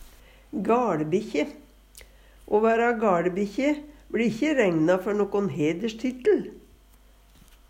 gaLebikkje - Numedalsmål (en-US)